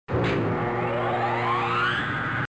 elevador
Sonido FX 18 de 42
elevador.mp3